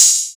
Open Hat.wav